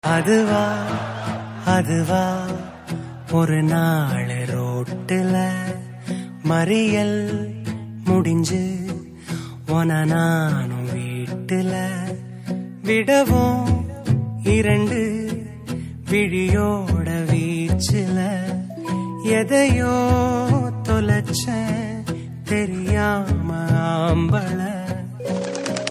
Category: Tamil Ringtones